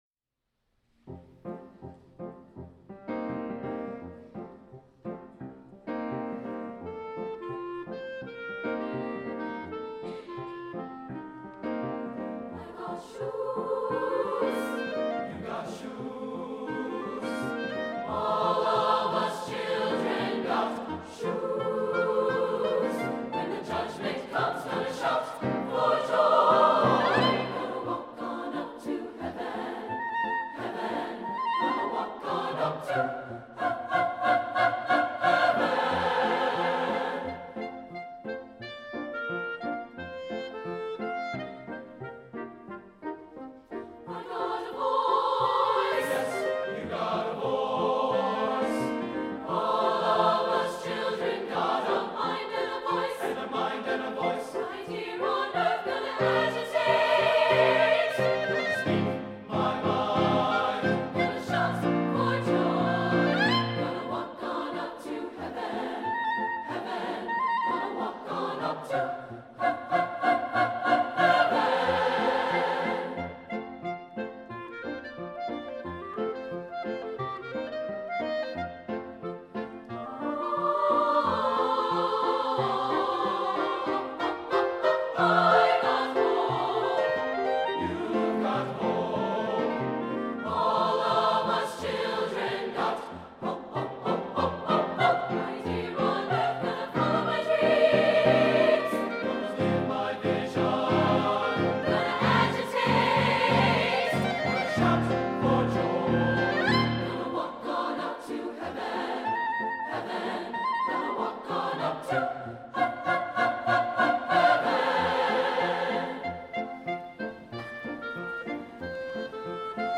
for SATB Chorus, Clarinet, and Piano (2004)